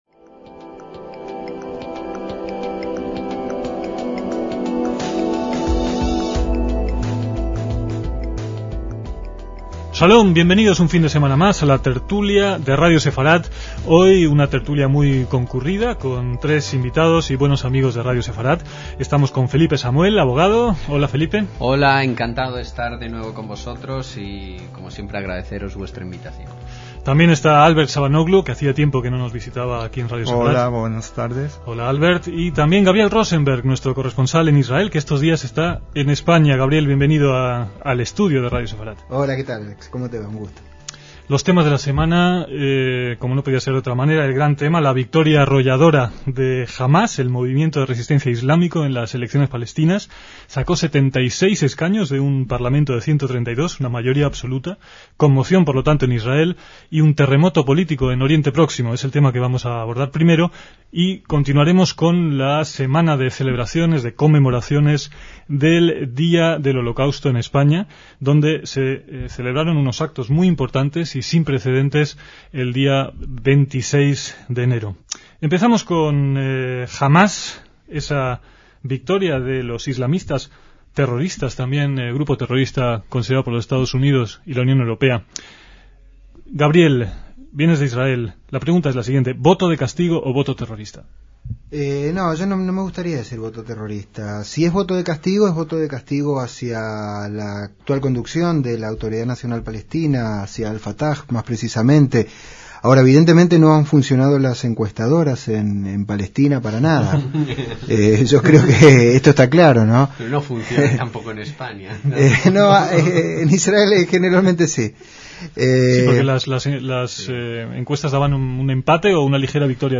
DECÍAMOS AYER (28/1/2006) - Era inevitable que en esta tertulia se abordaran comentarios sobre los actos del día precedente en Memoria del Holocausto, pero también se plantearon temas cuya actualidad entonces se prolonga hasta nuestros días.